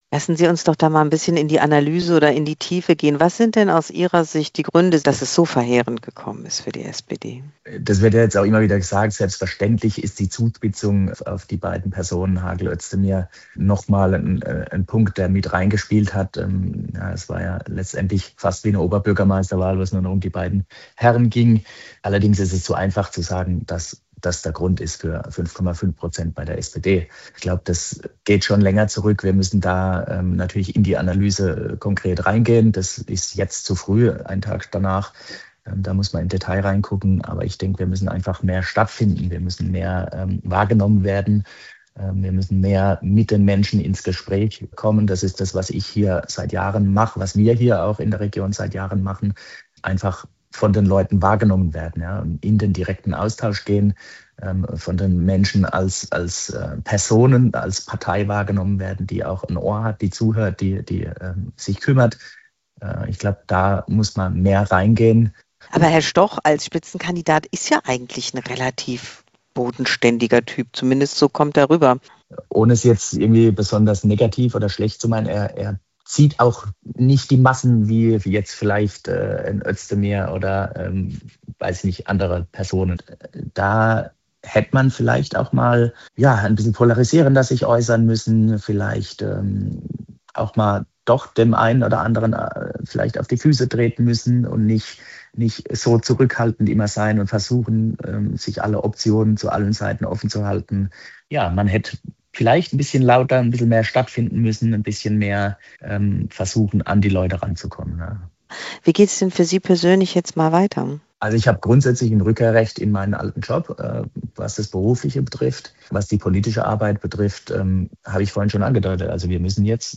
Interview mit SPD-Abgeordnetem Röderer: "Es war ja letztendlich fast wie eine Oberbürgermeisterwahl"
Trotzdem hat der SPD-Landtagsabgeordnete Jan-Peter Röderer sein Landtagsmandat verloren. Dazu äußert er sich im SWR-Interview.